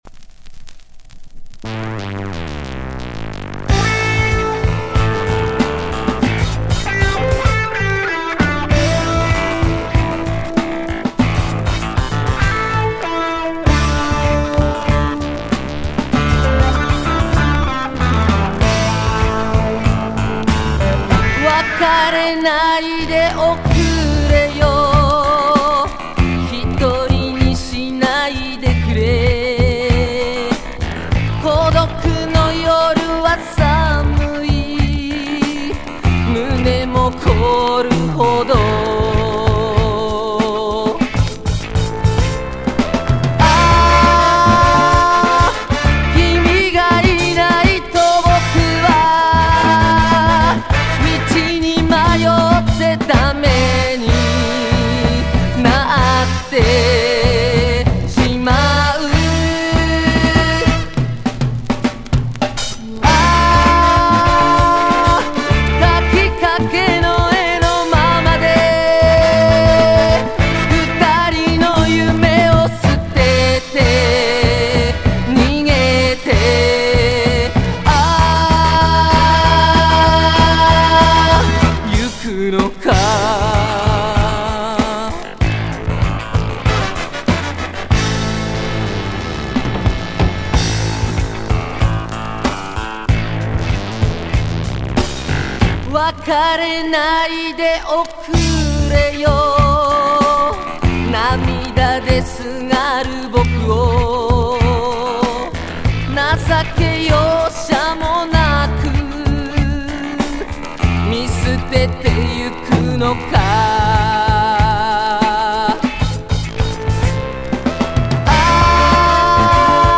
Запись была сделана с винила.